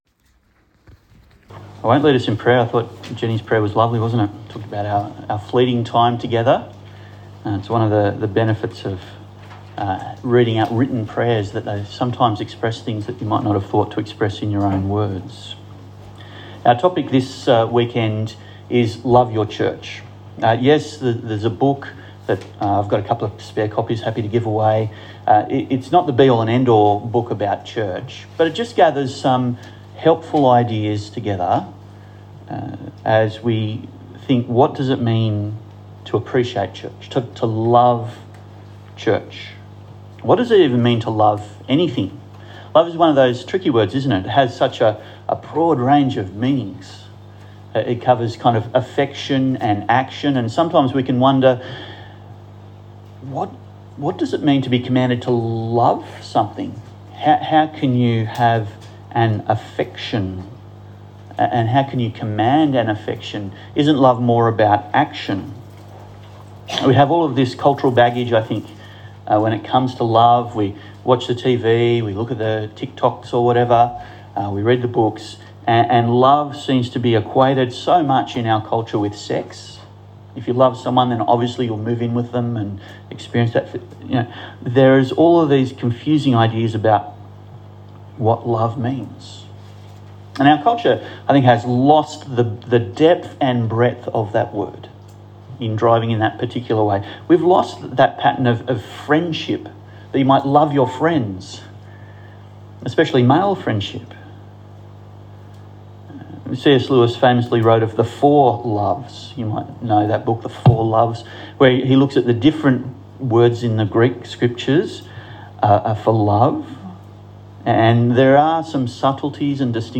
Love Your Church Passage: Ephesians 3:1-13 Service Type: Church Camp Talk